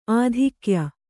♪ ādhikya